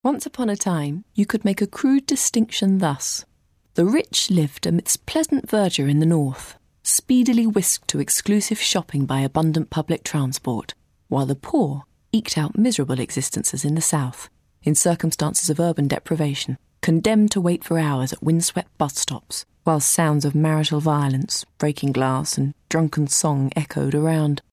Straight
Audio Book, Narration, Story Telling, Narrative